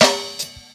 Medicated Snare 19.wav